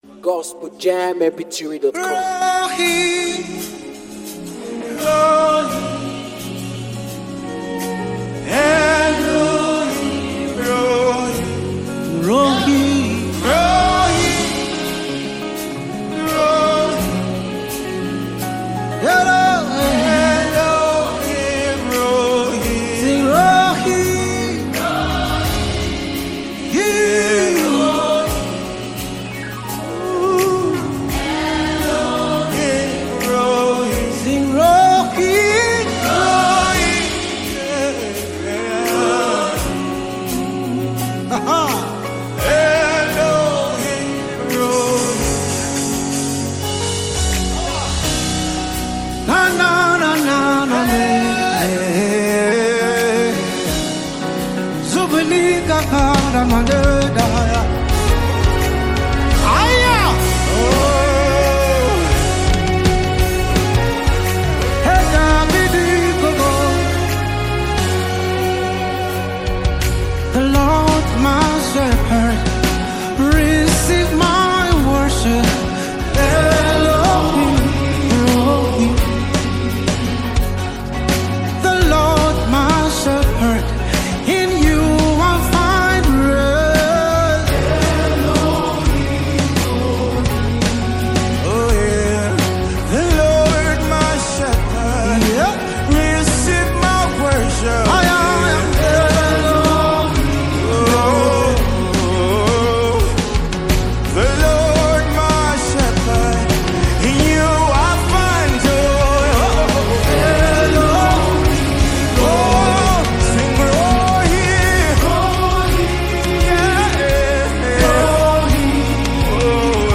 live worship